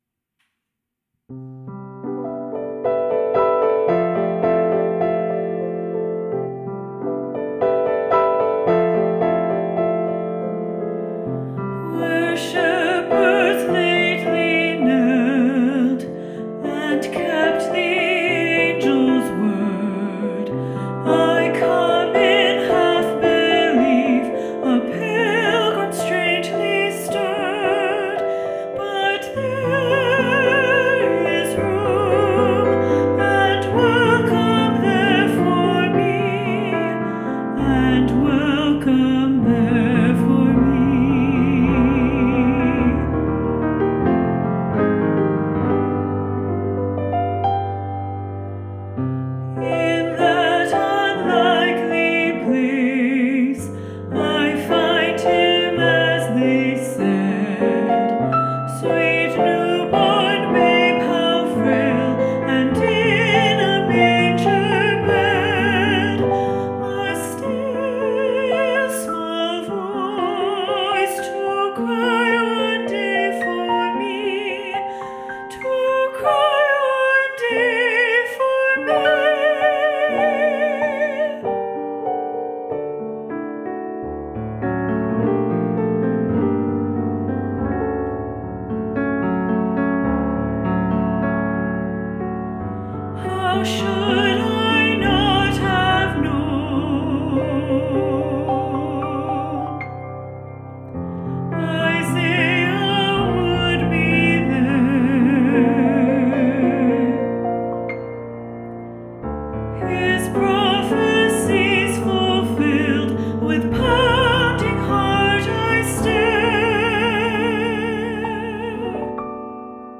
Vocal Solo High Voice/Soprano